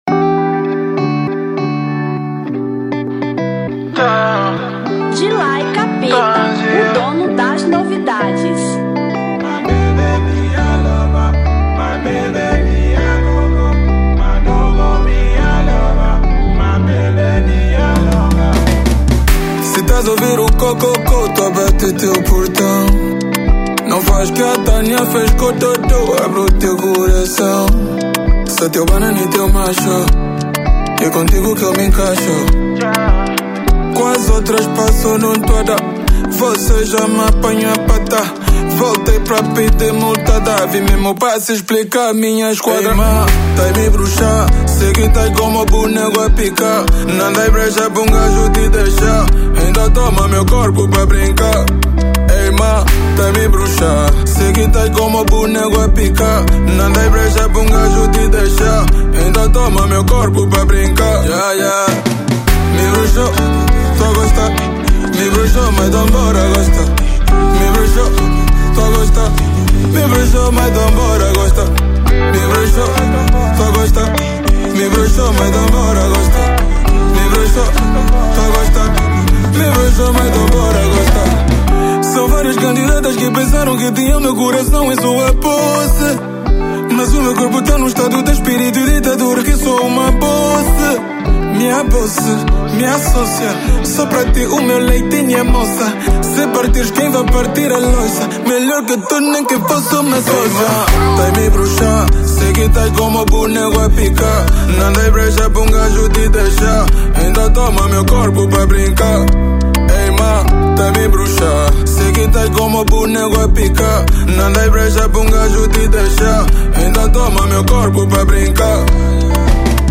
Kizomba 2024